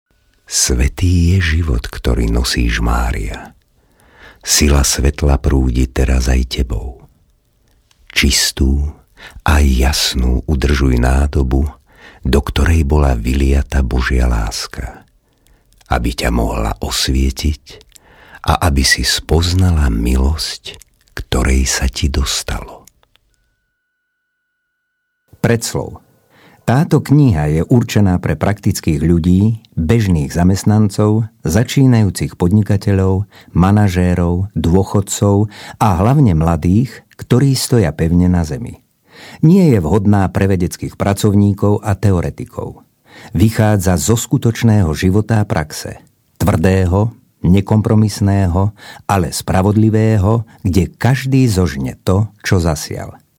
Professioneller slowakischer Sprecher für TV/Rundfunk/Industrie.
Kein Dialekt
Sprechprobe: Werbung (Muttersprache):
Professionell slovakian voice over artist